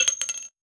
weapon_ammo_drop_07.wav